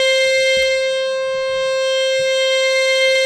52-key18-harm-c5.wav